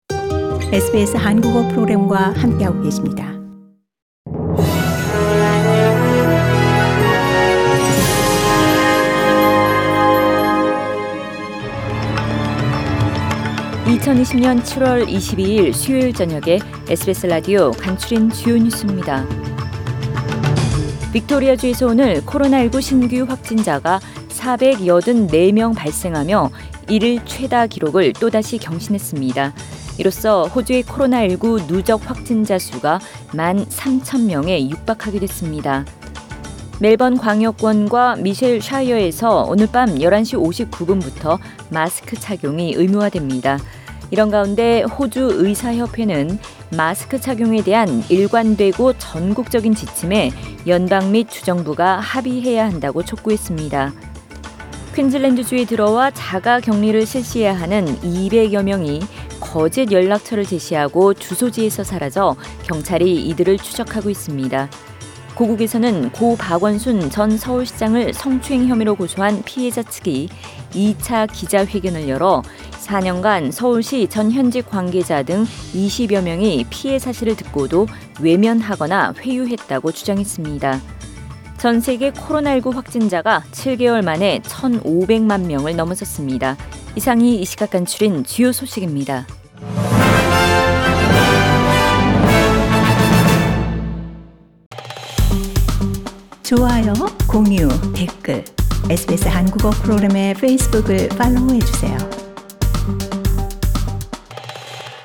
2020년 7월 22일 수요일 저녁의 SBS Radio 한국어 뉴스 간추린 주요 소식을 팟 캐스트를 통해 접하시기 바랍니다.